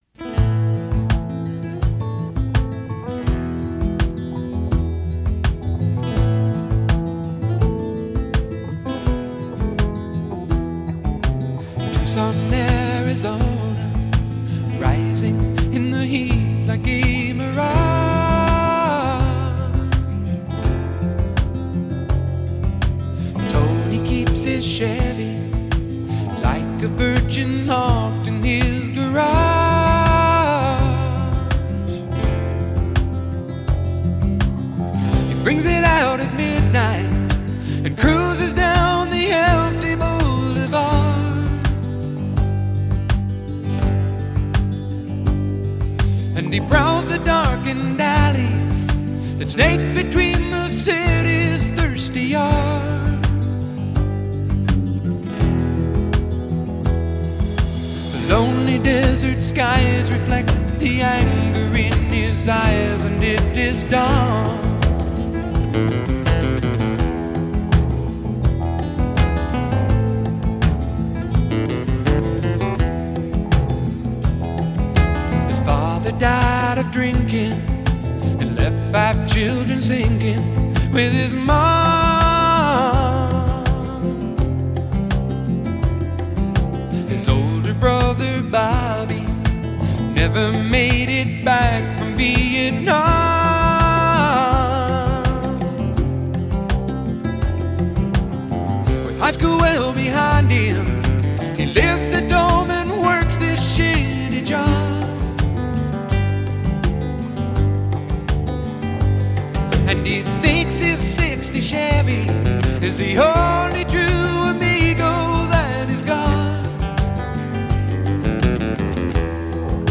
★ 美國最具代表性創作者之一，以輕柔歌聲、動人歌詞與吉他走紅70年代的民謠詩人！